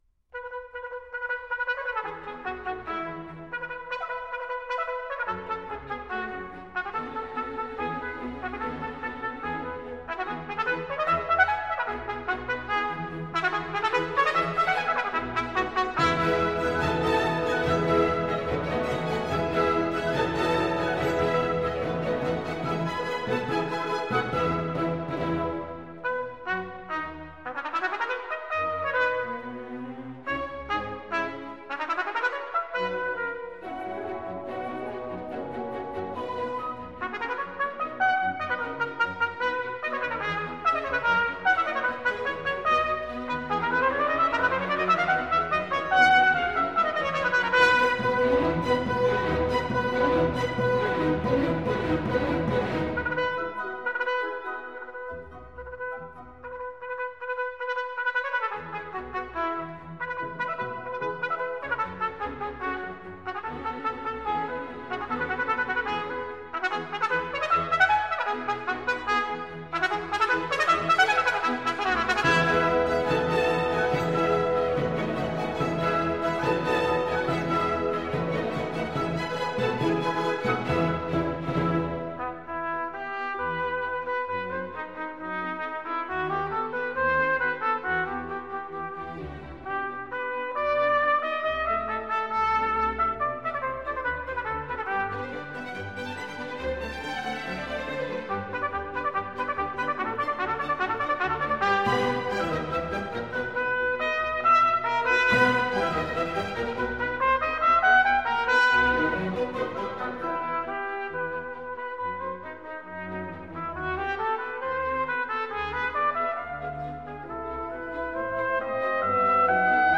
DDD STEREO
最后， 乐曲在热烈的气氛中结束。